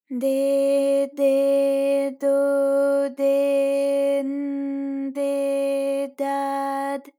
ALYS-DB-001-JPN - First Japanese UTAU vocal library of ALYS.
de_de_do_de_n_de_da_d.wav